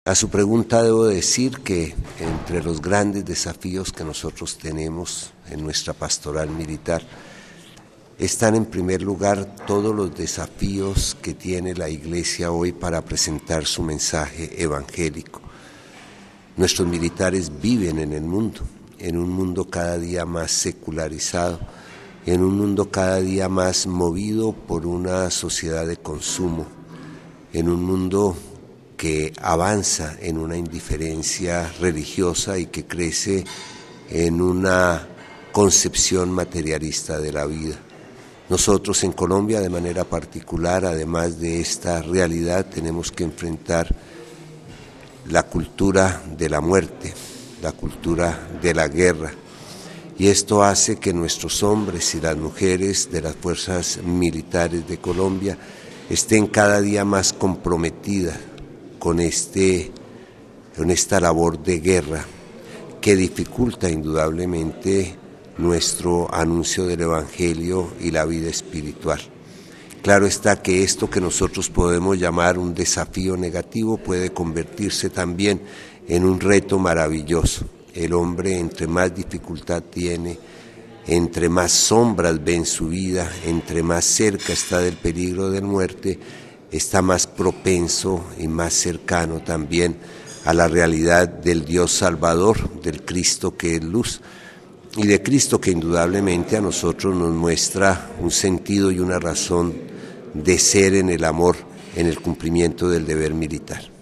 Entrevista al obispo castrense de Colombia, monseñor Suescun